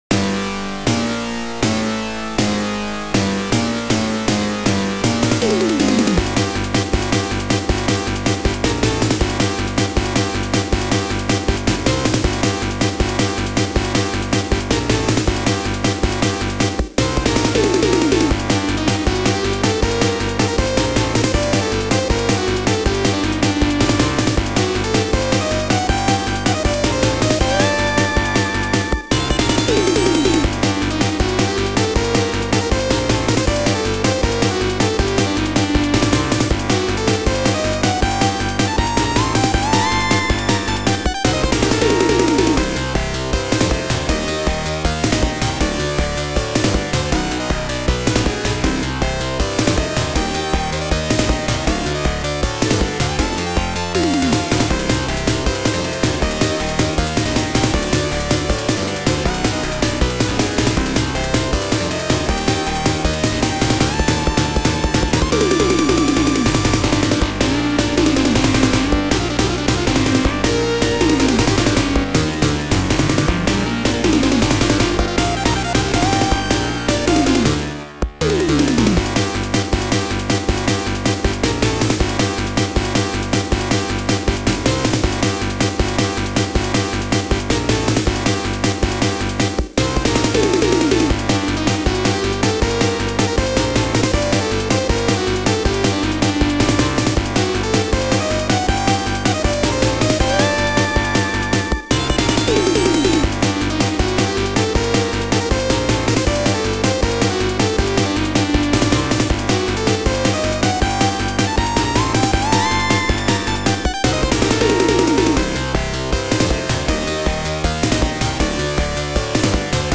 [16-Bit_YM2151 Arcade]